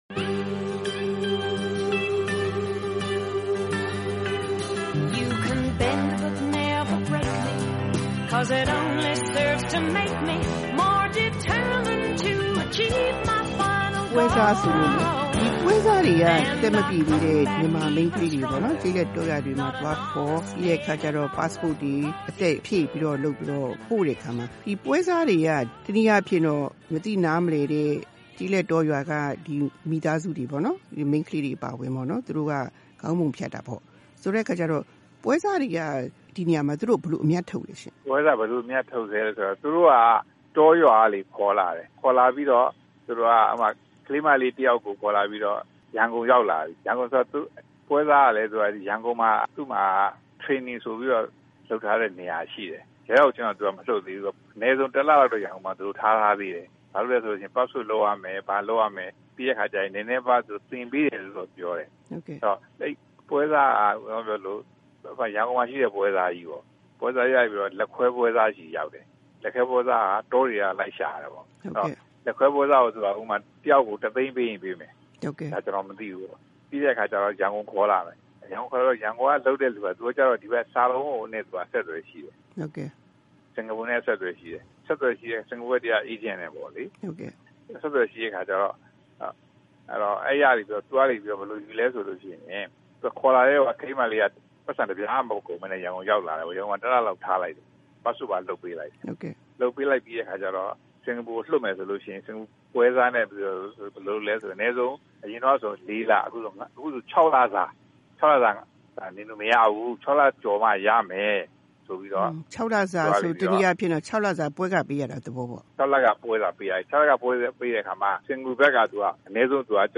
ဆက်သွယ်မေးမြန်းပြီး ဒီသီတင်းပတ်ရဲ့အမျိုးသမီးကဏ္ဍမှာ တင်ပြထားပါတယ်။